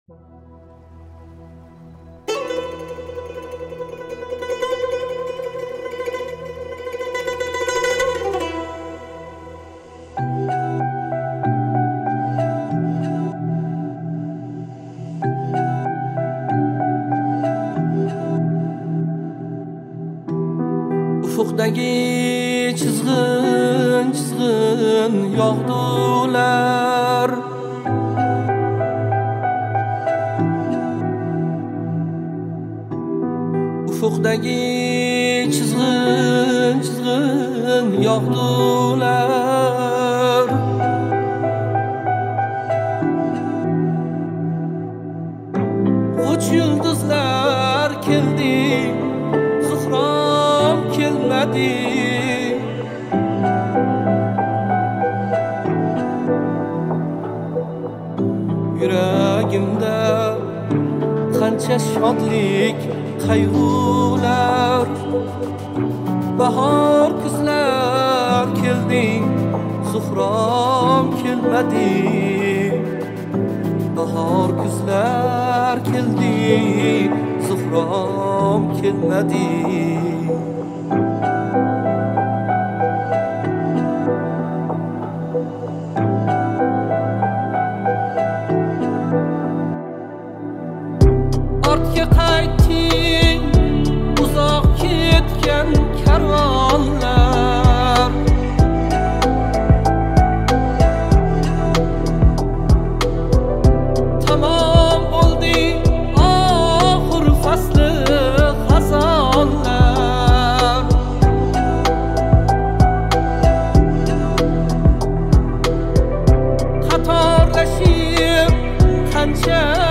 • Узбекские песни